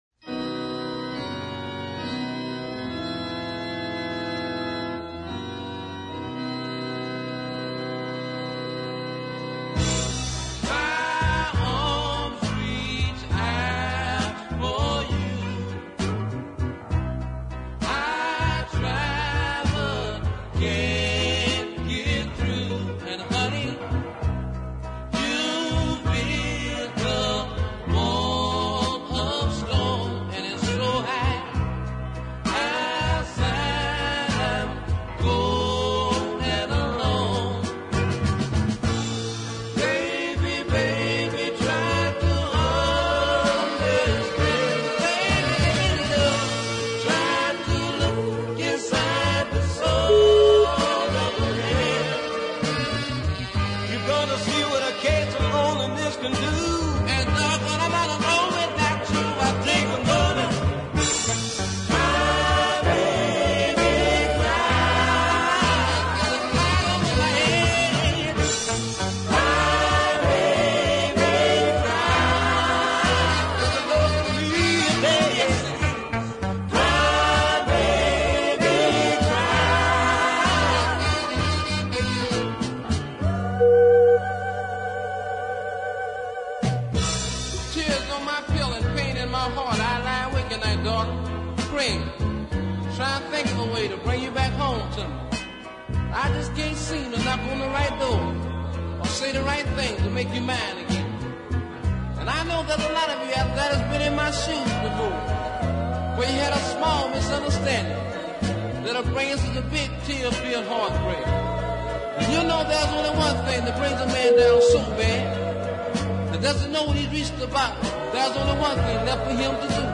This fine male duo